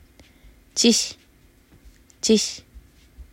チㇱ　　　　　　　cis         泣く